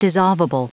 Prononciation du mot dissolvable en anglais (fichier audio)
Prononciation du mot : dissolvable